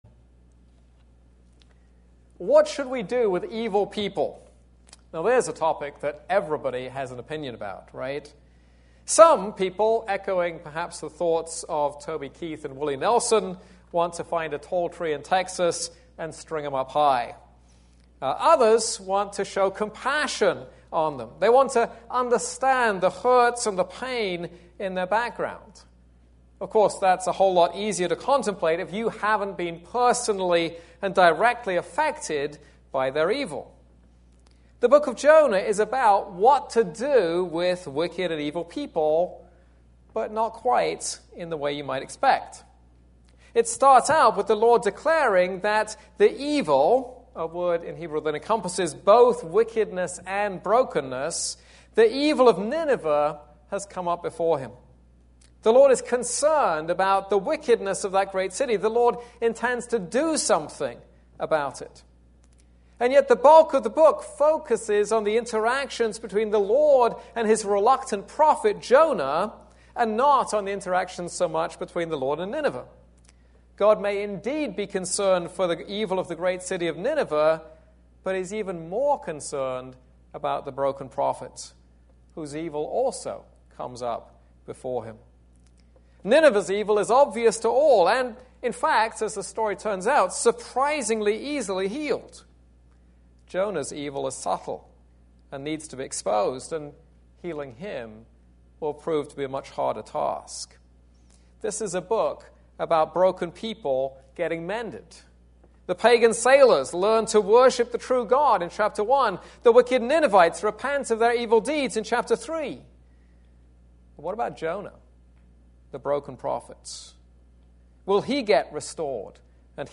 This is a sermon on Jonah 1:1-17.